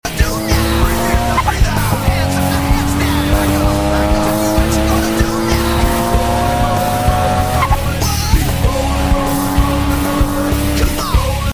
Nissan Cefiro a31 rb 25 sound effects free download